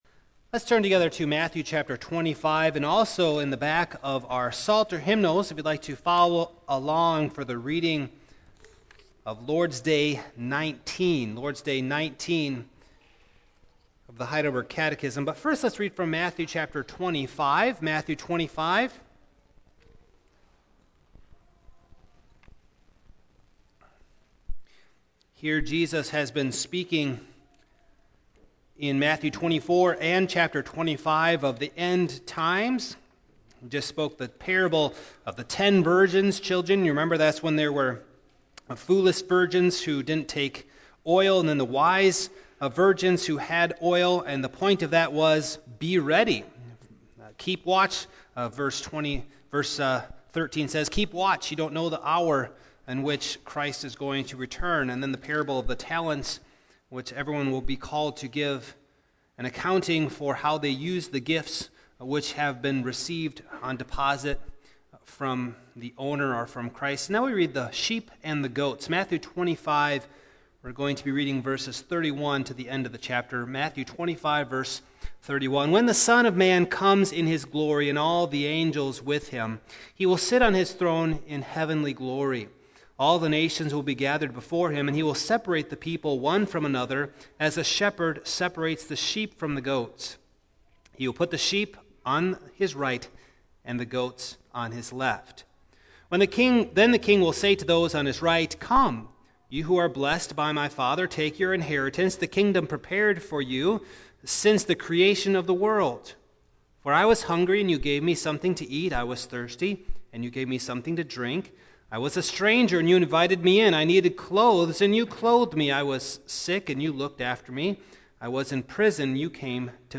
2017 His Rule and Return Preacher